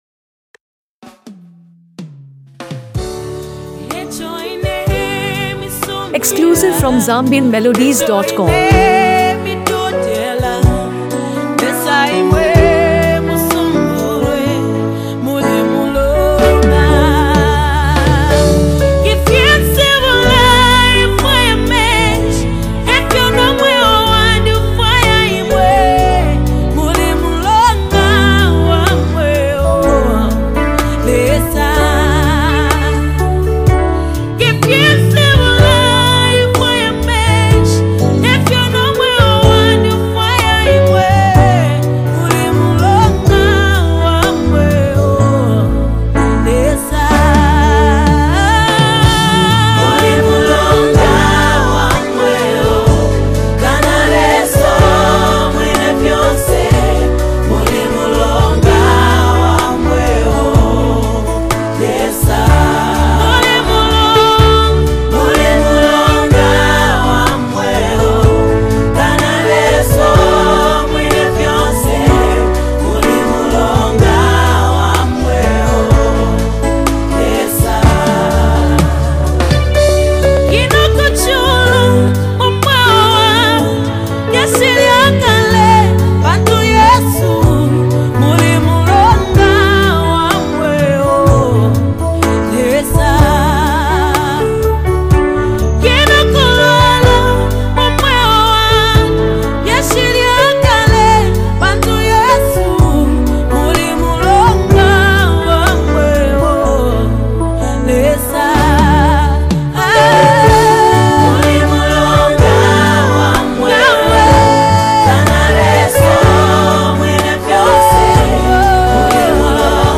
Zambian Music
a stirring gospel masterpiece
crystal-clear vocals carry both warmth and power
Over a foundation of subtle drums and acoustic guitar